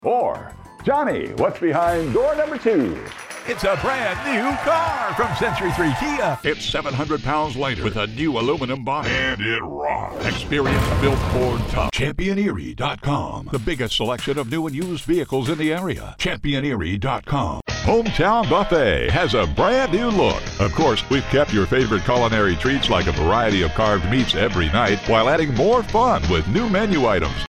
Male
English (North American)
Commercial, Guy next door, Bright, Believable, Confident, Friendly, Warm, Deep, Informative, Authoritative, Happy, Mature
Television Spots
Broadcast Tv